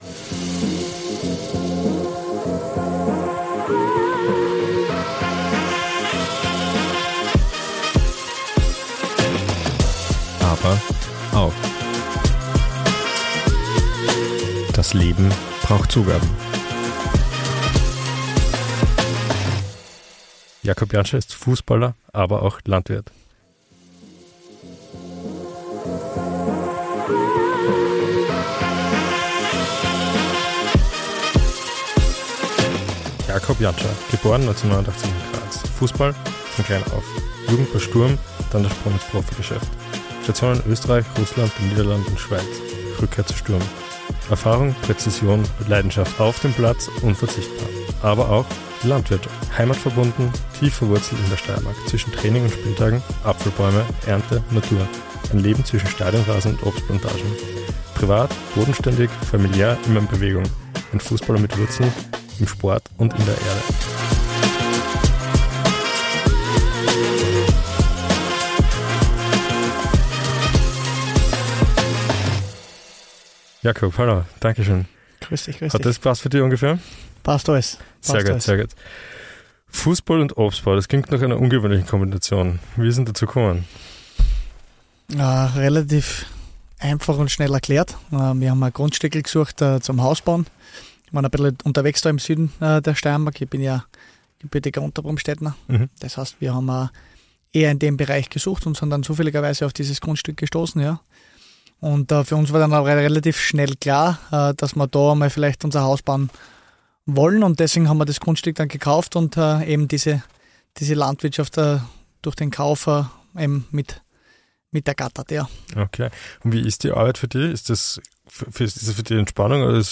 Beschreibung vor 1 Jahr Für die aktuelle von ABER AUCH hat es uns mit dem PODMOBIL erstmals aufs Land verschlagen. Unweit von Graz liegt die Landwirtschaft von Fußballer Jakob Jantscher.